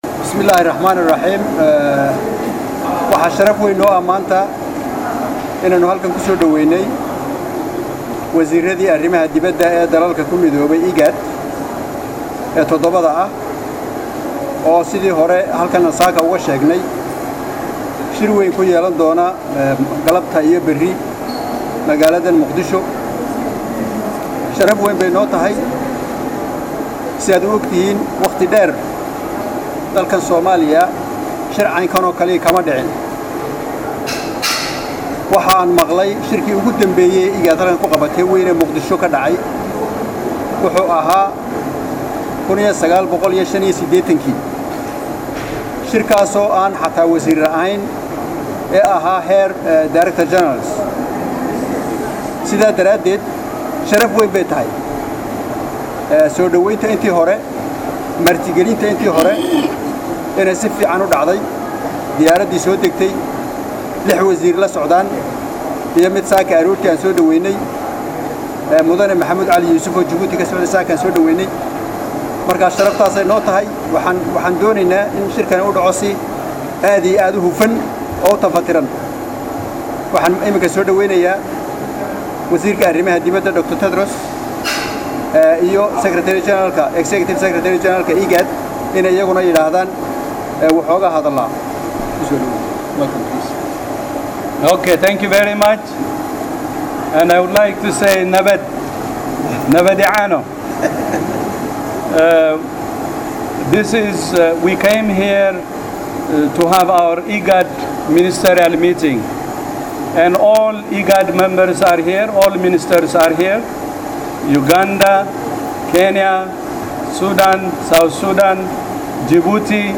DHAGEYSO: SHIRKA JARAA'ID EE WASIIRRADA WADDAMADA IGAD
Wasiirka arrimaha dibedda Itoobiya, Dr .Tedros Adhanom, xoghayaha guud ururka IGAD, Maxbuub Macalin iyo sii hayaha xilka wasaaradda arrimaha dibadda Somalia, C/raxmaan Ducaale Bayle ayaa garoonka diyaaradaha Muqdisho si wadajir ah shir jaraa’id ugu qabtay.
DHAGEYSO_Shirkii_Jaraa'id_ee_Garoonka_Muqdisho.mp2